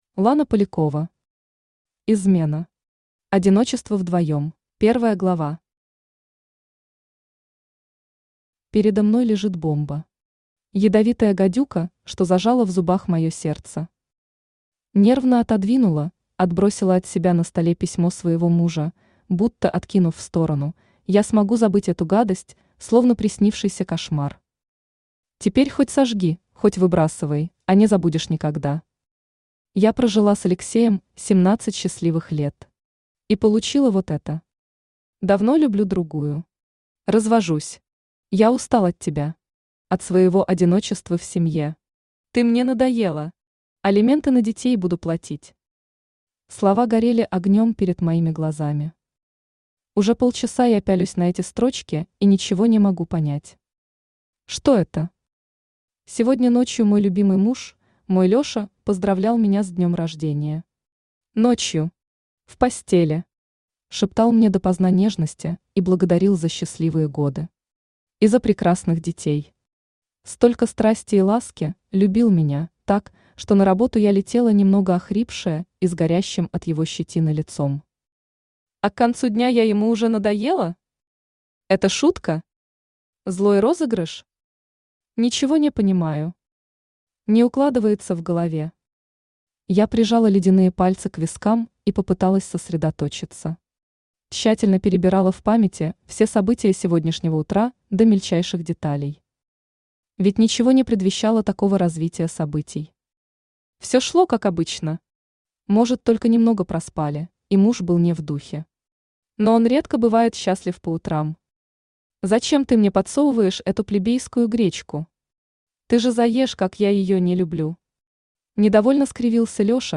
Аудиокнига Измена. Одиночество вдвоем | Библиотека аудиокниг
Одиночество вдвоем Автор Лана Полякова Читает аудиокнигу Авточтец ЛитРес.